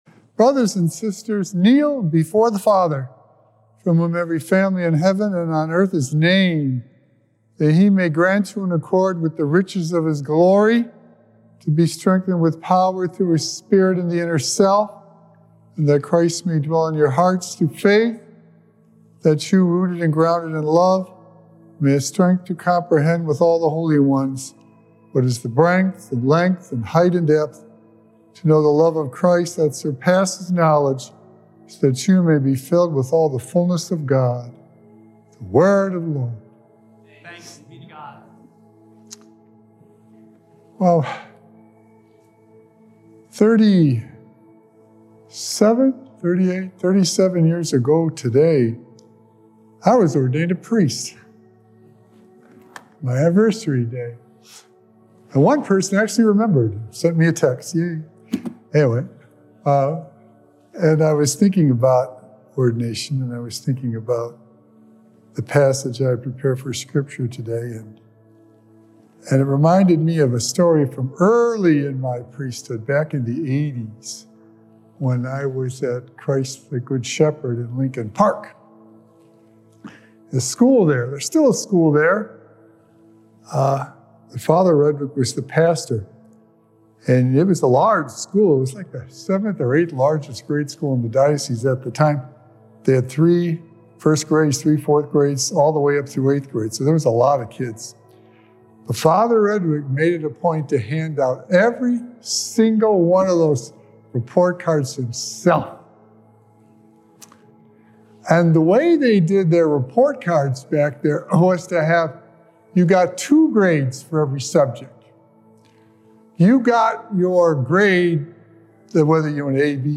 Sacred Echoes - Weekly Homilies Revealed
Recorded on Friday, November 15th, 2024 at St. Malachy Catholic Church.